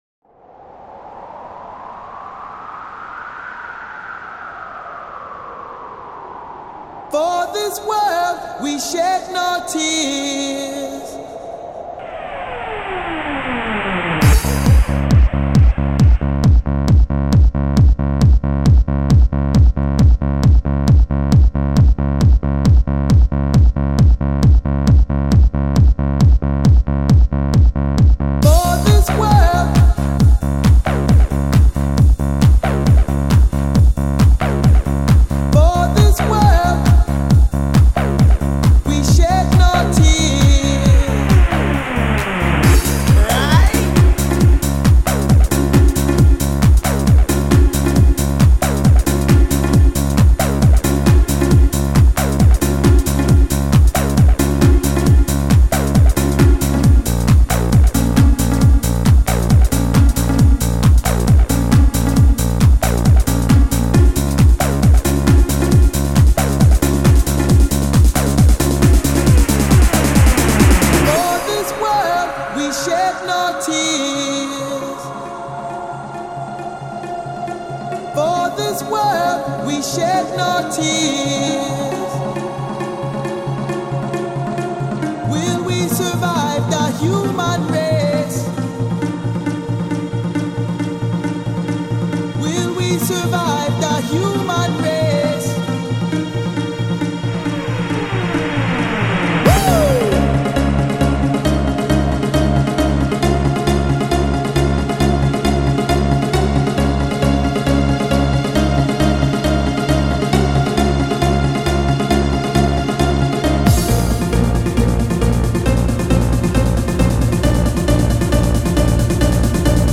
Жанр: Dance